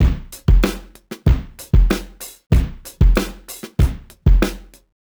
Index of /musicradar/sampled-funk-soul-samples/95bpm/Beats
SSF_DrumsProc2_95-02.wav